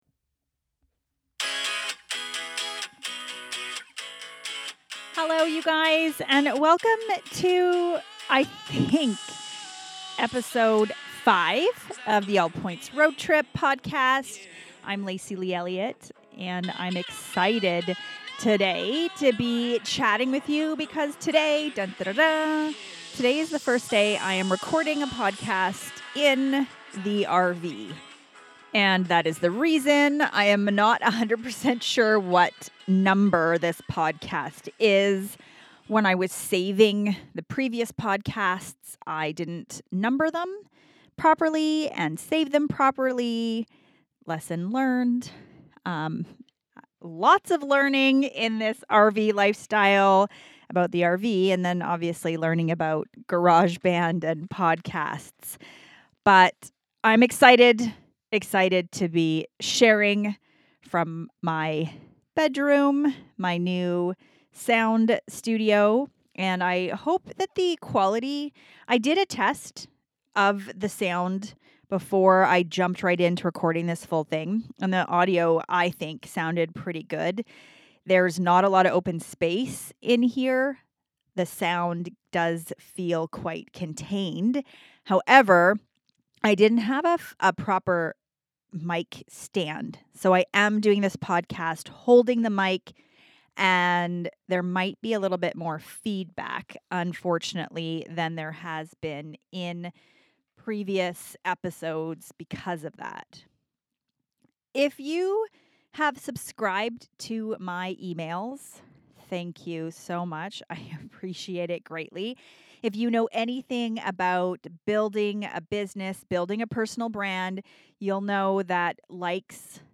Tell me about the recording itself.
This is the first recording that I have done from my RV!